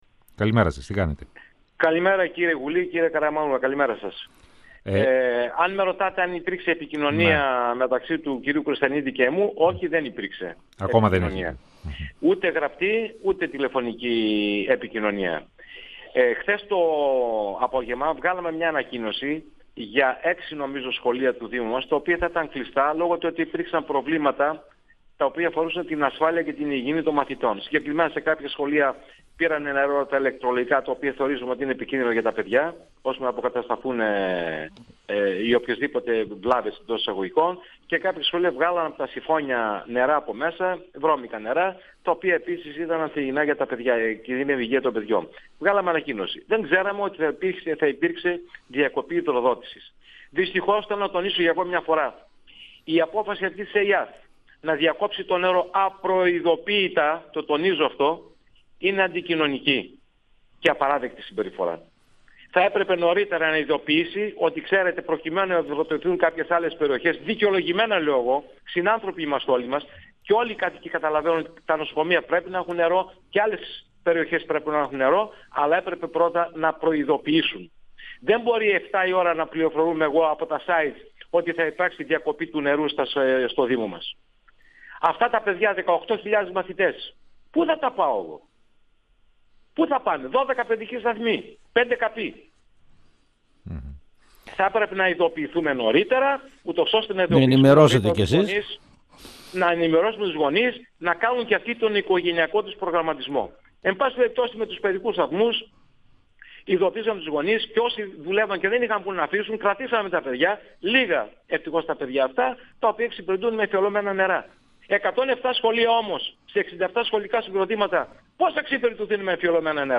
Ο δήμαρχος Κορδελιού – Ευόσμου, Πέτρος Σούλας, στον 102FM του Ρ.Σ.Μ. της ΕΡΤ3
Συνέντευξη